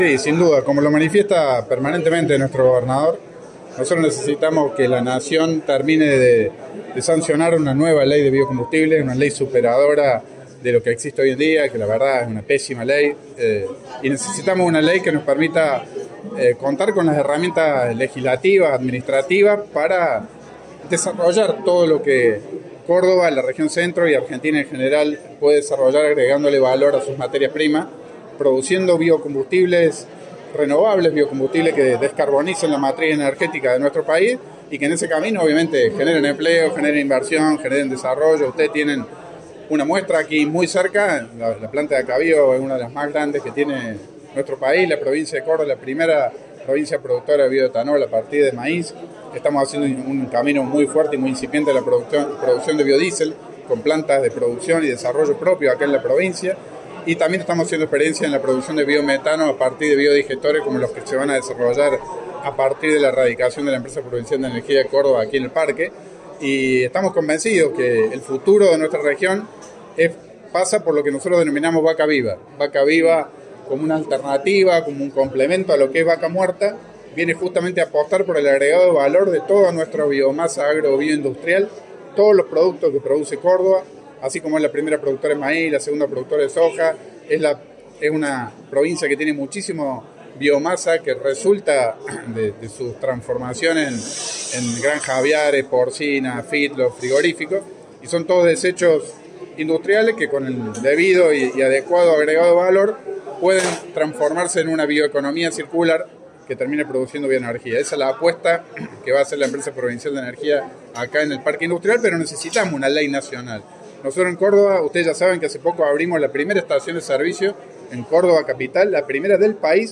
El ministro de Infraestructura y Servicios Público de la Provincia, Fabián López, estuvo en Villa María en el marco de un convenio entre el municipio local, EPEC, el Parque Industrial y la participación del Gobierno de Córdoba. En el marco de ese encuentro, el ministro dialogó con la prensa y recordó el fuerte reclamo que nunca persistió de parte de la Provincia hacia la Nación por las retenciones.